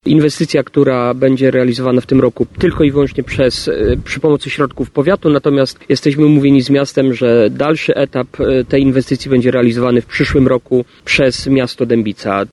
Pierwsze 600 metrów ma powstać do połowy grudnia a kolejne 600 metrów w przyszłym roku – mówi Adam Pieniążek wicestarosta dębicki.